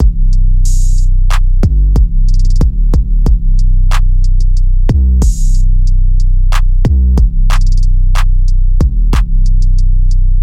描述：beat loops
标签： 808 beat beats drums
声道立体声